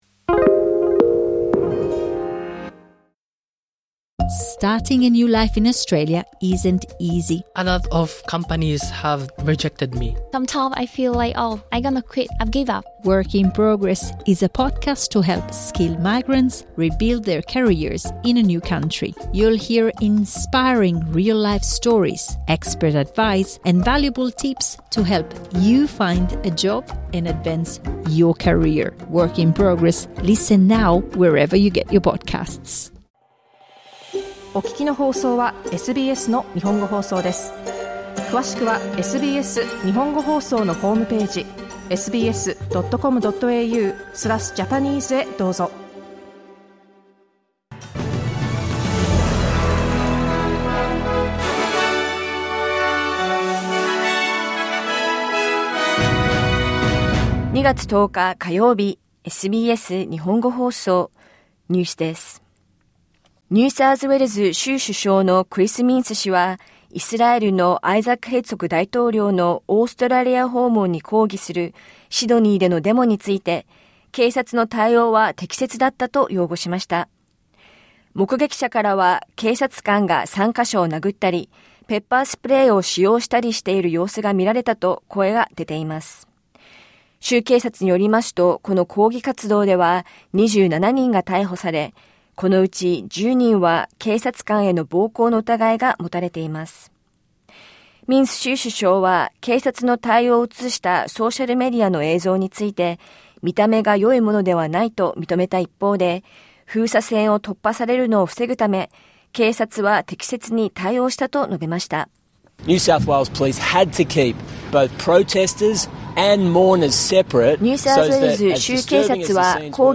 SBS日本語放送ニュース2月10日火曜日